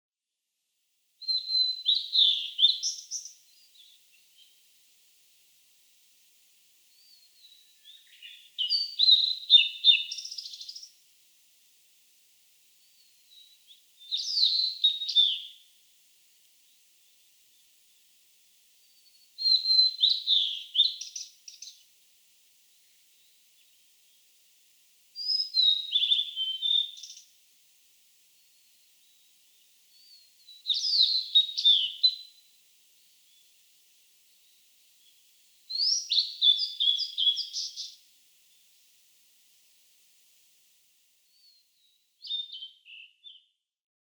一度は見てみたい森の小鳥　オオルリ
10程度のパターンを不規則にくり返し、全体として複雑な印象を与える。たとえば「ピーリーリー、ポイヒーピピ、ピールリピールリ、ジェッジェッ」など